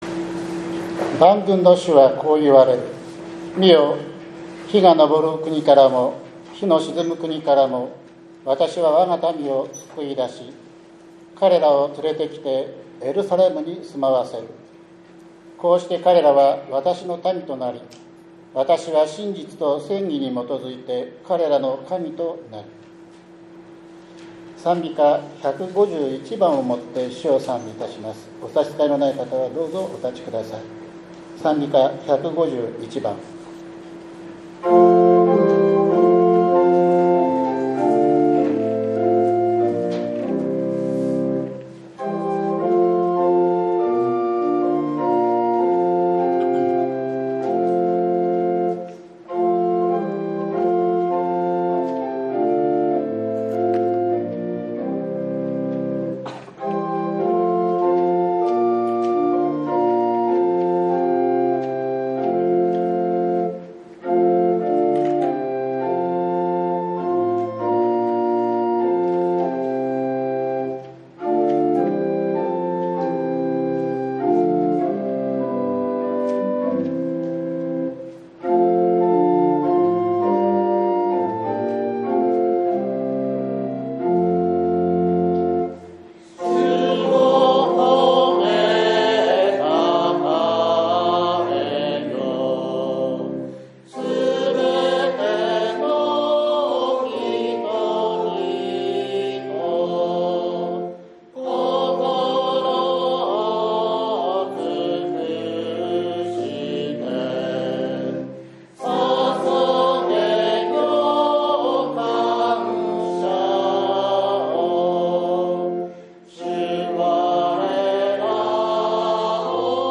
７月６日（日）主日礼拝